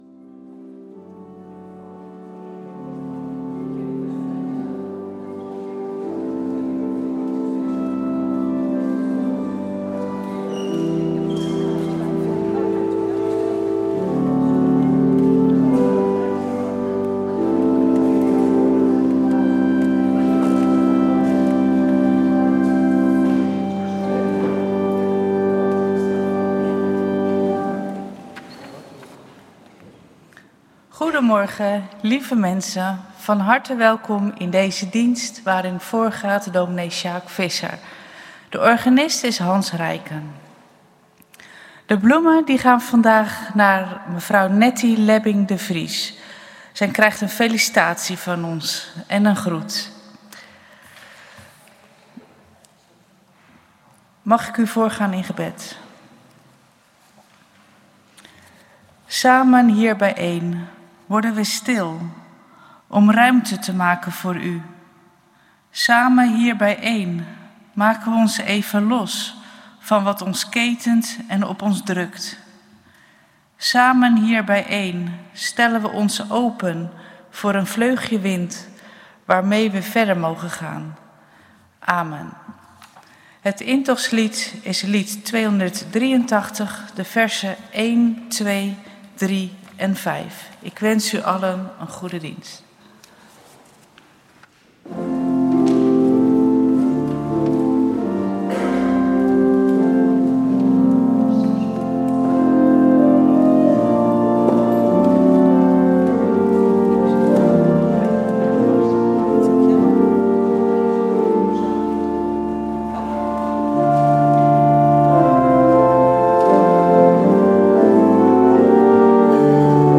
U vindt ons in de Grote Kerk van Oostzaan voor kerkdiensten en onze activiteiten.
Kerkdienst geluidsopname